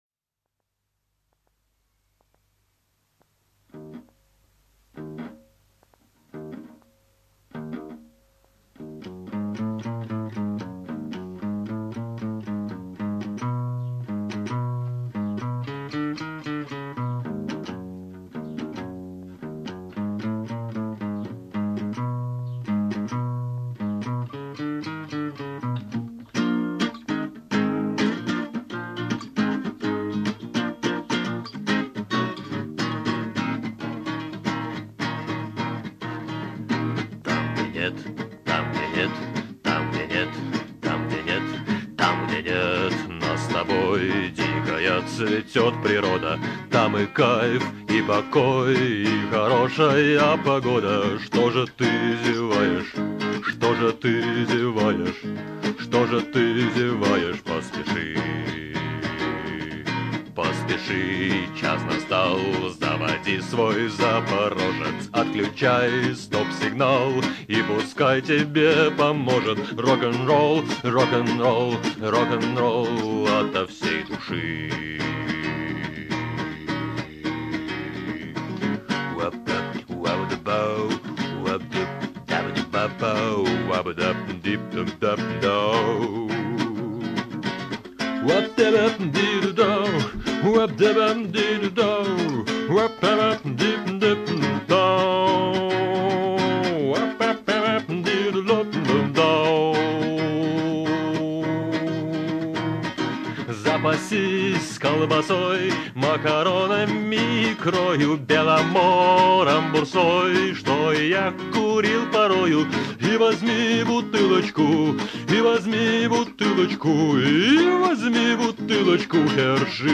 песни 1992-97 гг. в исполнении автора.
ХХ века в собственной мастерской (правда – скульптурной).
дённых в формат mp3, 128kbps, 44kHz, stereo: